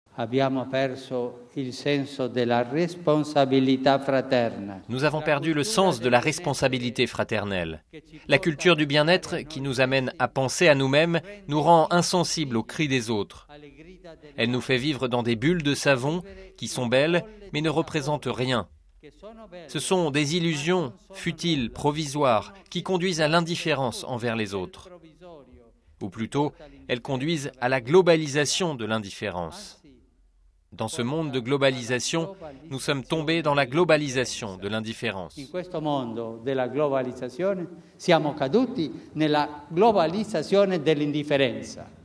Quelques mois après son élection, en juillet, le Pape François a choisi, pour son premier déplacement hors de Rome, de se rendre à Lampedusa. C’est depuis cette petite île italienne qu’il a dénoncé le drame de l’immigration en évoquant la « globalisation de l’indifférence » RealAudio